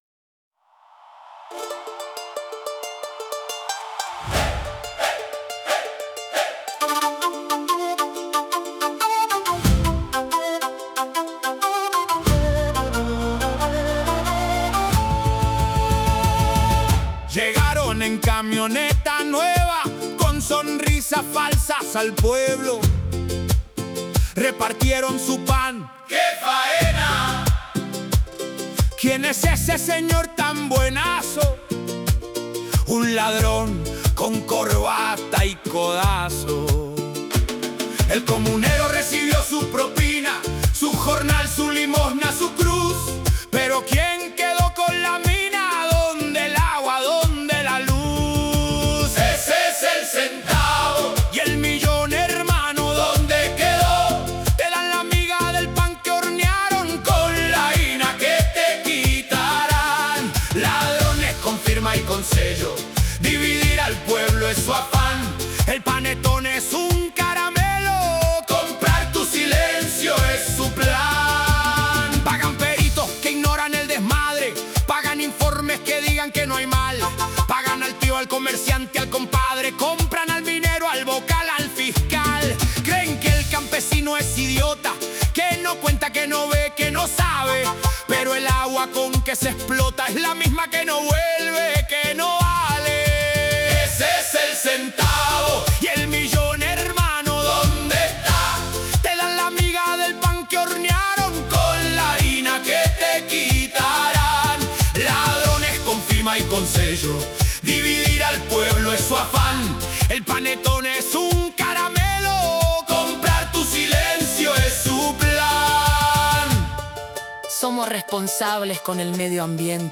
Huayno